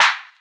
Clap [Racks].wav